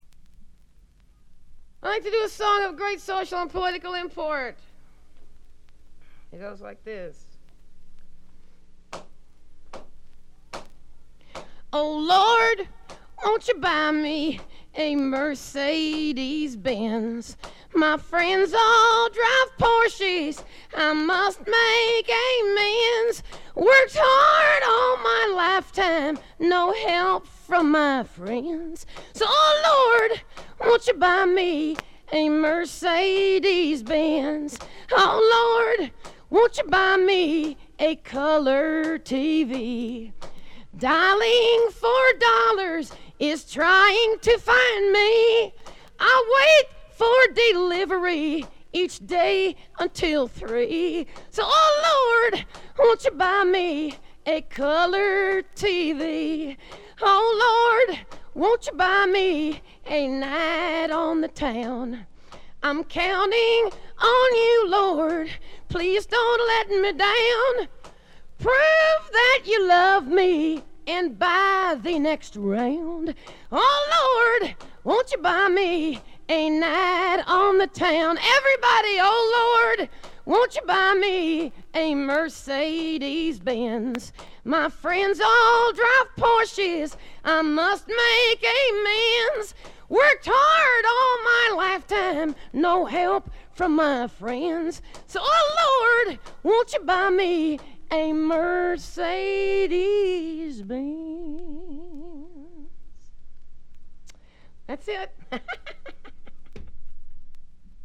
B3序盤で3連のプツ音。これ以外は細かなバックグラウンドノイズ、チリプチ少々。
試聴曲は現品からの取り込み音源です。